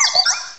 cry_not_piplup.aif